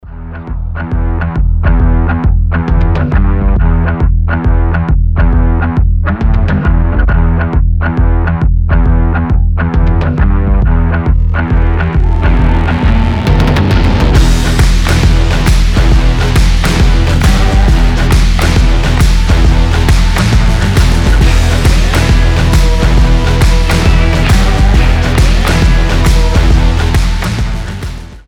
• Качество: 320, Stereo
гитара
брутальные
без слов
Alternative Rock
post-Britpop